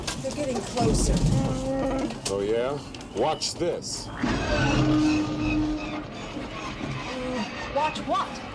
The sound of the plane's engines failing as they run out of fuel is the same as that of the erratic hyperdrive engine of the Millennium Falcon in Star Wars: The Empire Strikes Back.
hyperdrive fail
hyperdrive-fail.mp3